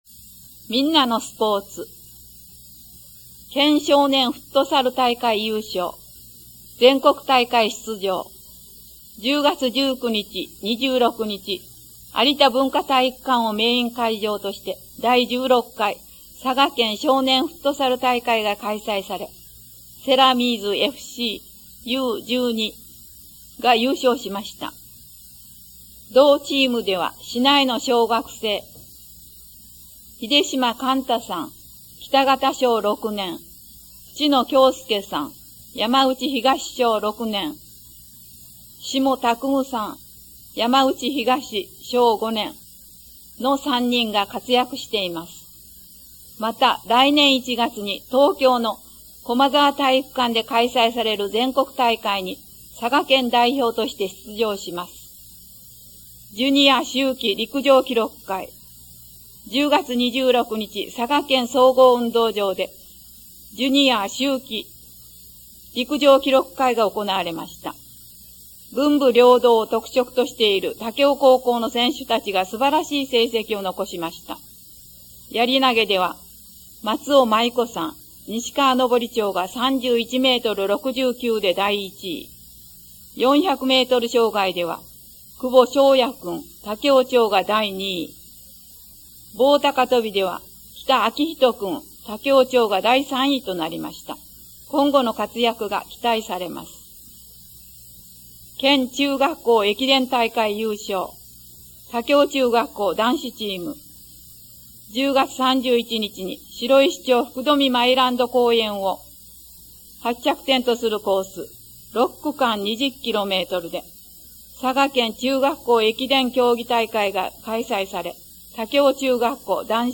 また、音訳ボランティア「エポカル武雄フレンズ」のご協力により、音読データをMP3形式で提供しています。